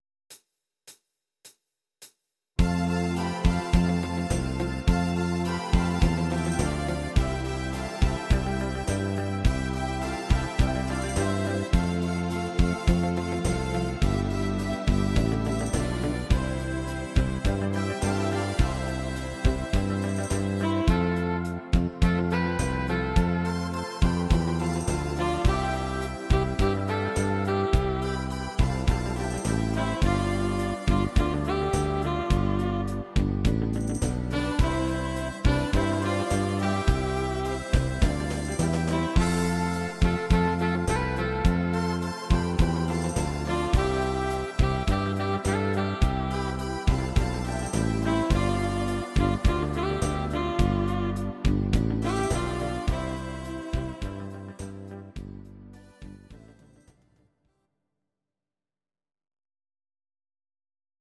These are MP3 versions of our MIDI file catalogue.
Please note: no vocals and no karaoke included.
rhumba